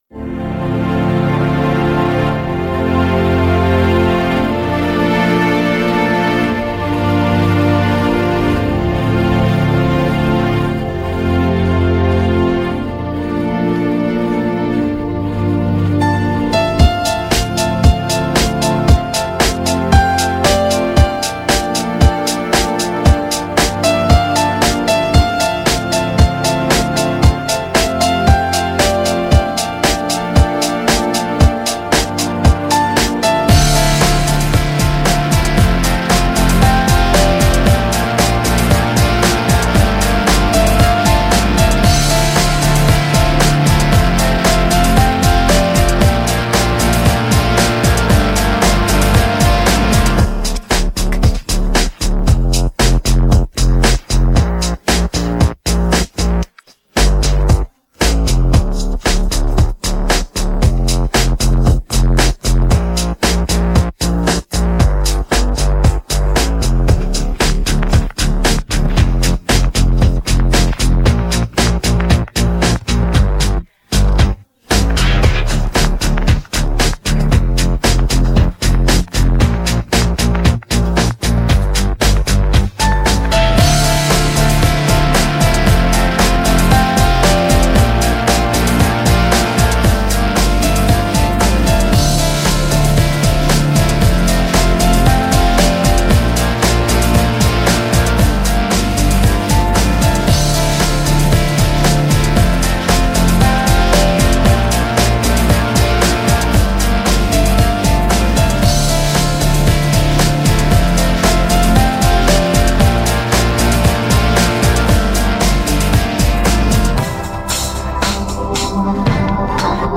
российская поп-группа